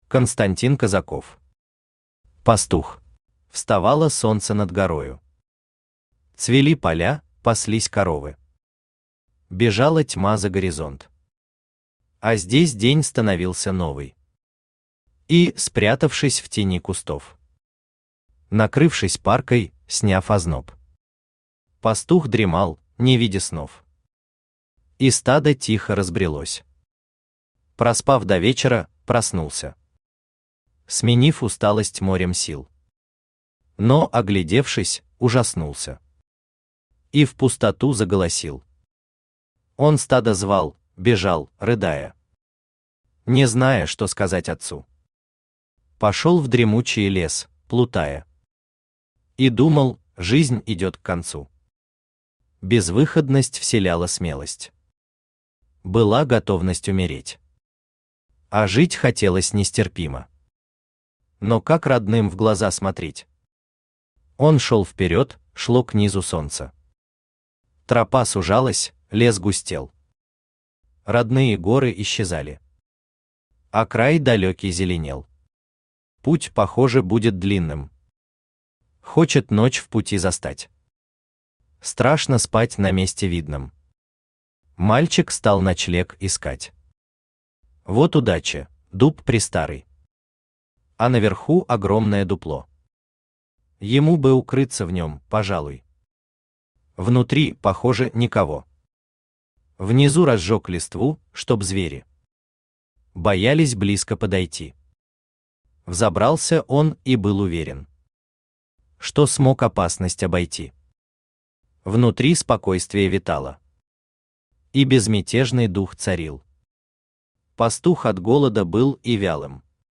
Аудиокнига Пастух | Библиотека аудиокниг